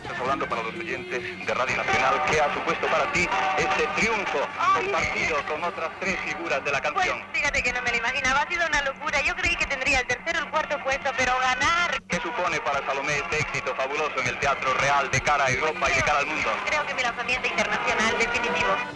Fragment de l'entrevista a Salomé, una de les quatre guanyadores del Festival d'Eurovisió